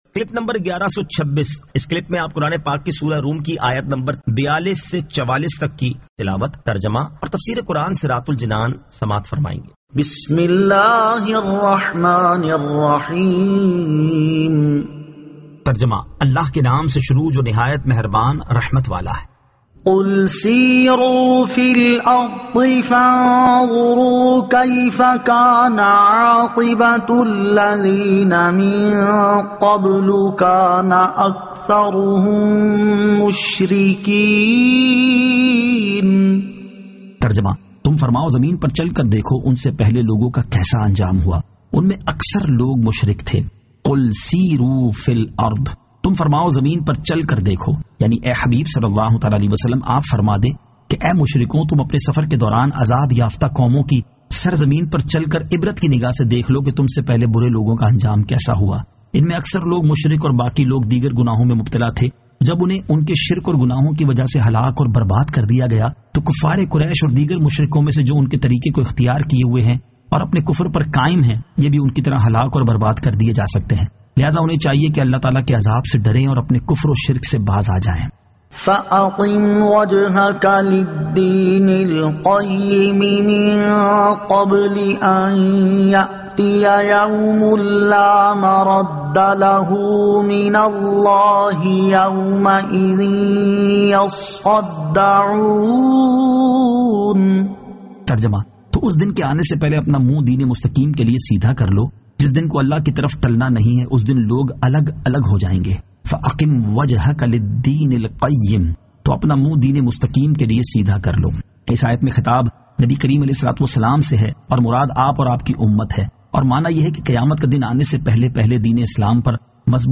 Surah Ar-Rum 42 To 44 Tilawat , Tarjama , Tafseer